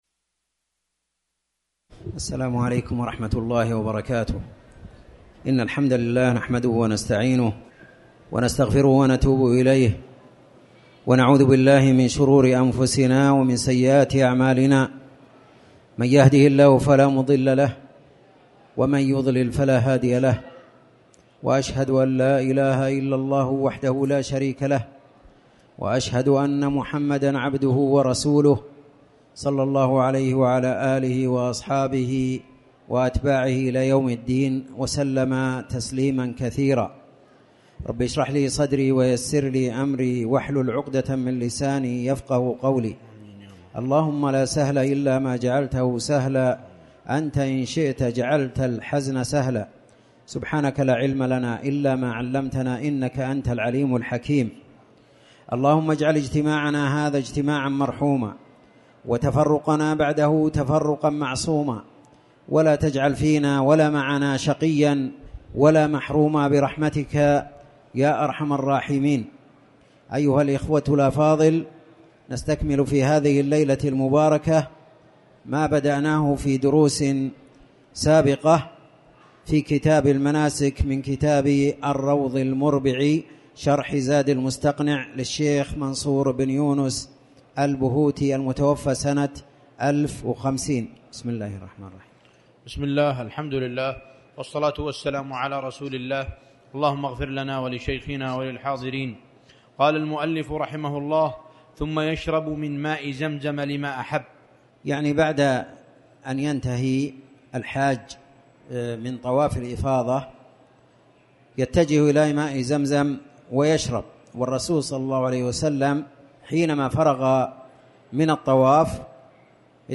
تاريخ النشر ١٨ صفر ١٤٣٩ هـ المكان: المسجد الحرام الشيخ